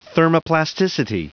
Prononciation du mot thermoplasticity en anglais (fichier audio)
thermoplasticity.wav